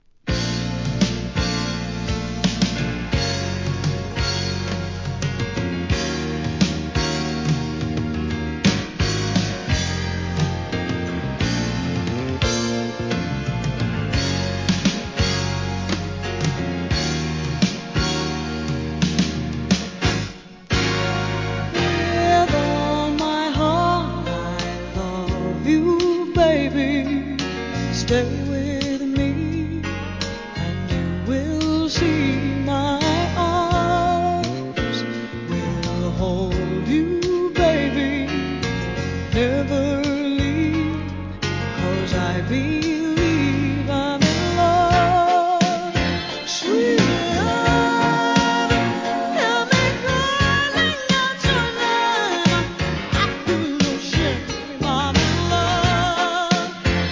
SOUL/FUNK/etc...
1986年ヒットの人気メロ〜・♪